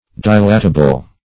Search Result for " dilatable" : The Collaborative International Dictionary of English v.0.48: Dilatable \Di*lat"a*ble\, a. [Cf. F. dilatable.]